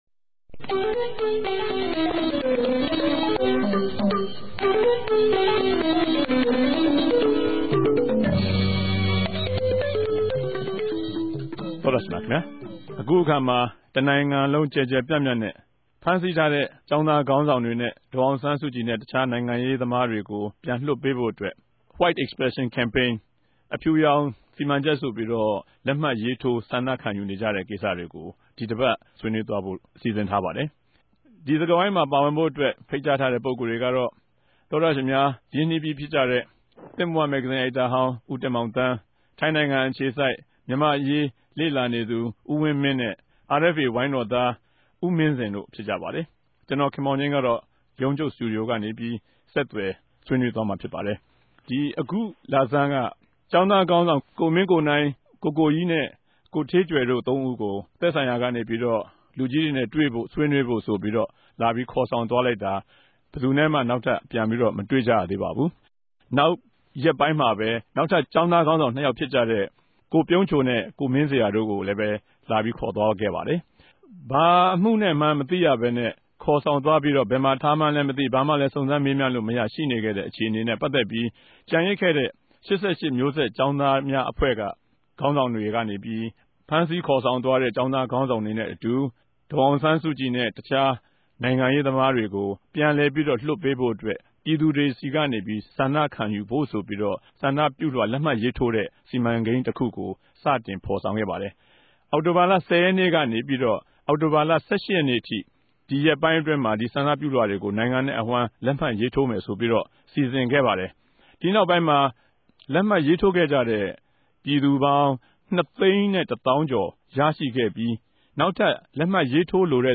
တပတ်အတြင်းသတင်းသုံးသပ်ခဵက် စကားဝိုင်း (၂၀၀၆ အောက်တိုဘာလ ၁၄ရက်)
တယ်လီဖုန်းနဲႛ ဆက်သြယ် မေးူမန်း္ဘပီး တင်ဆက်ထားတာကို နားထောငိံိုင်ပၝတယ်။